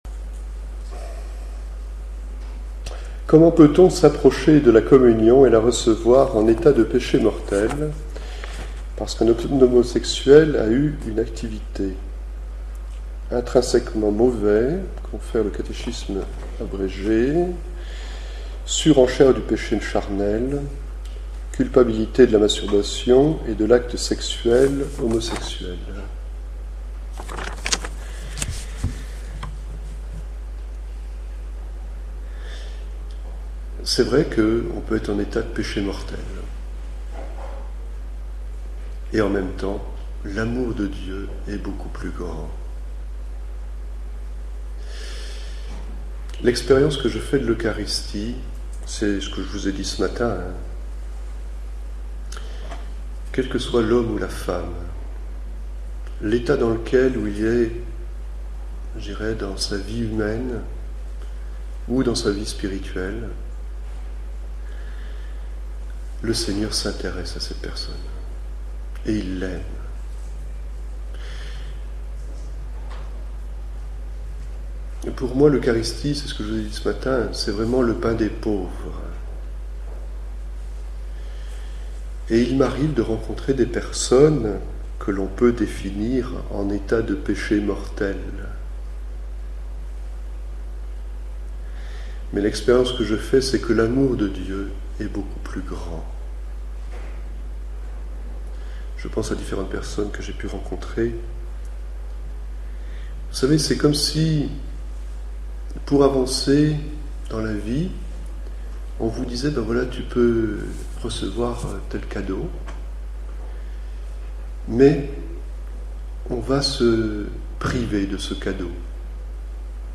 Journée d’enseignement et de partage